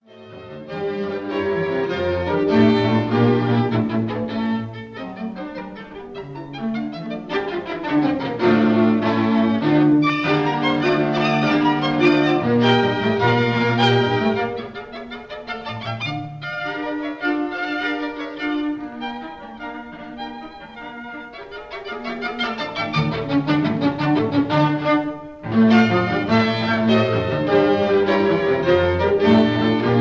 violin
viola